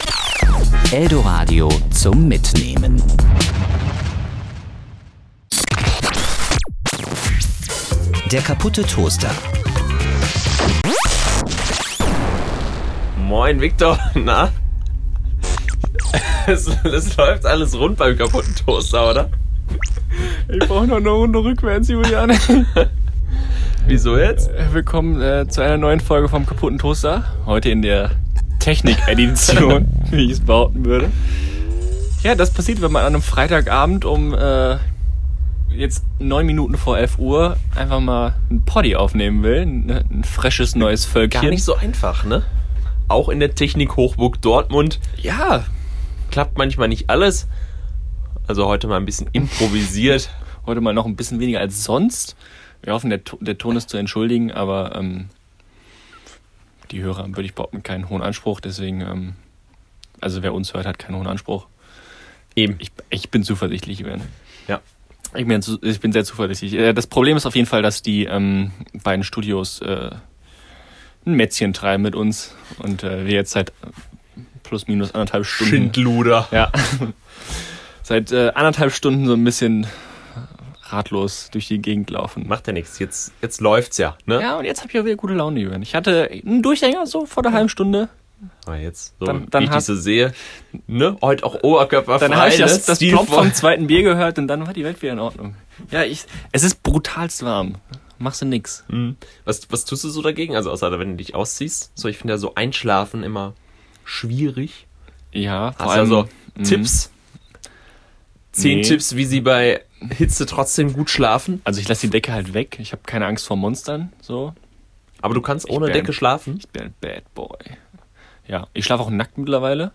Und sie zaubern eine hammerwitzige neue Kategorie aus dem Hut. Und auch technische Probleme meistern sie bravourös.